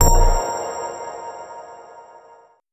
confirm-selection.wav